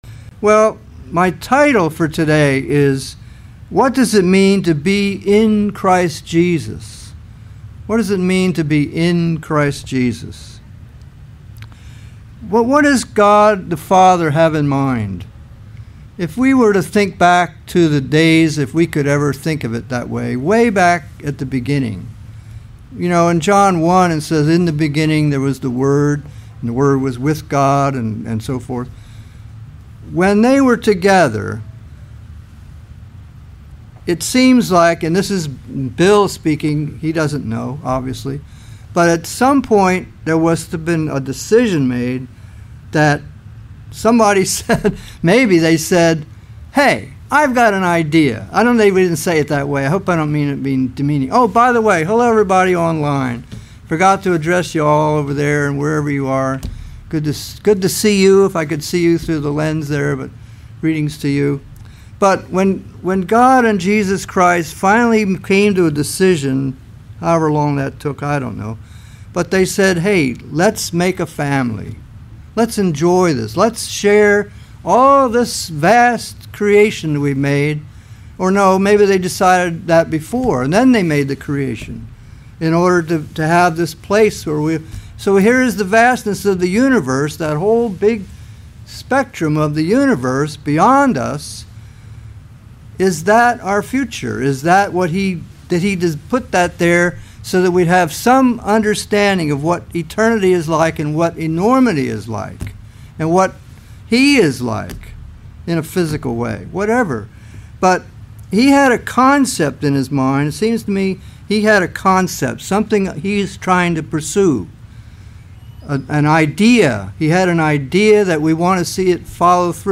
Sermons
Given in Vero Beach, FL